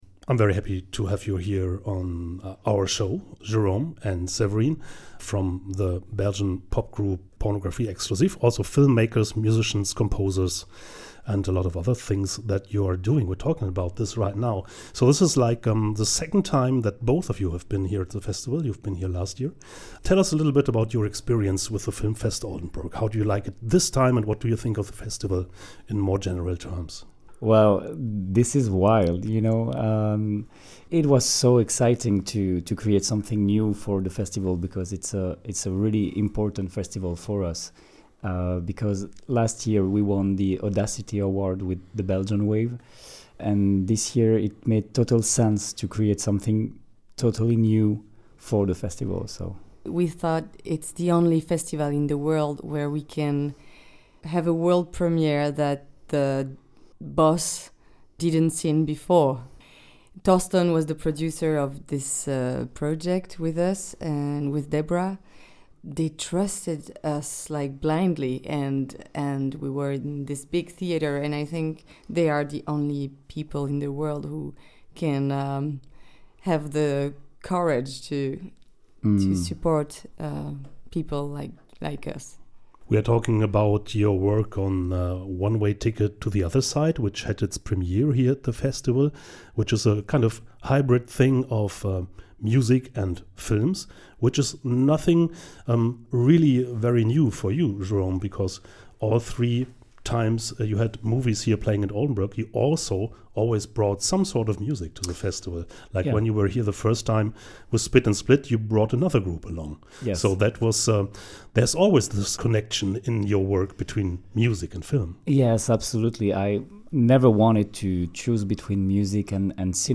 Interview mit Pornographie Exclusive – soundundvision
Radiointerview